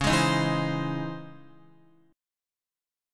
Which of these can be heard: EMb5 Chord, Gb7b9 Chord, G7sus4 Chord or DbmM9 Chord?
DbmM9 Chord